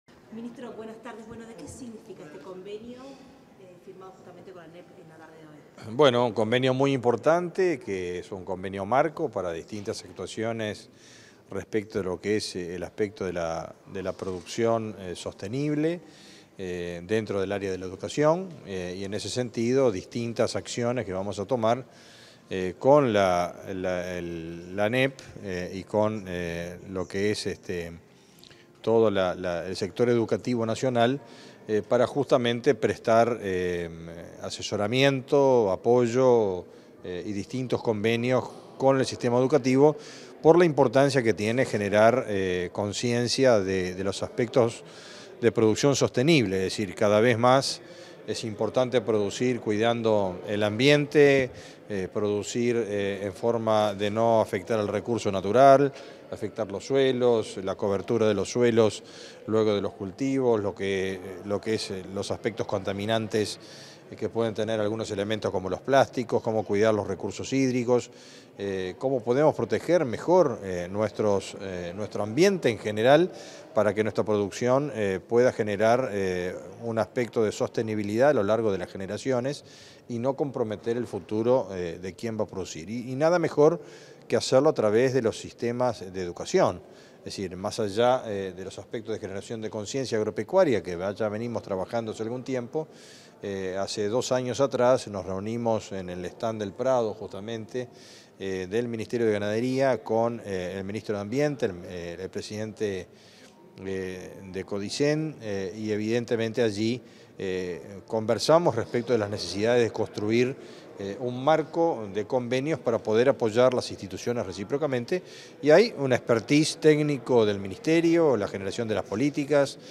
Declaraciones del ministro de Ganadería, Fernando Mattos
Declaraciones del ministro de Ganadería, Fernando Mattos 07/09/2023 Compartir Facebook X Copiar enlace WhatsApp LinkedIn Tras la firma de un convenio marco de cooperación de la Administración Nacional de Educación Pública (ANEP) con el Ministerio de Ganadería, Agricultura y Pesca (MGAP), este 7 de setiembre, el titular de la cartera, Fernando Mattos, dialogó con la prensa.
Mattos prensa.mp3